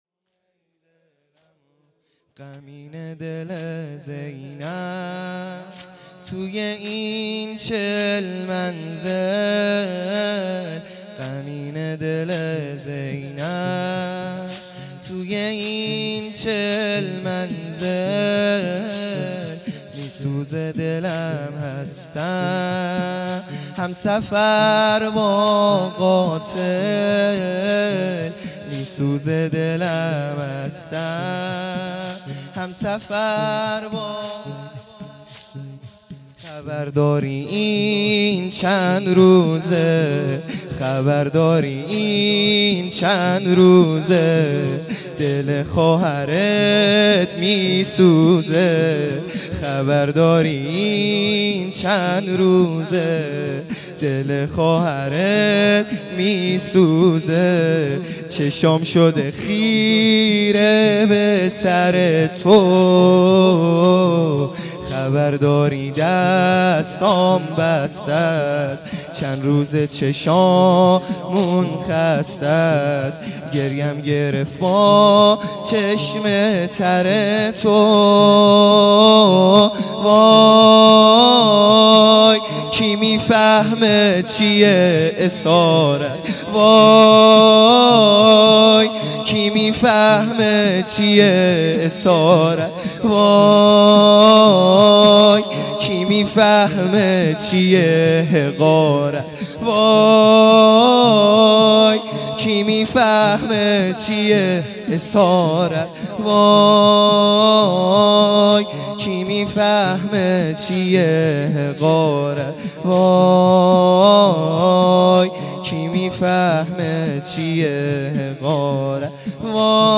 زمینه | غمینه دل زینب
هجدهم محرم الحرام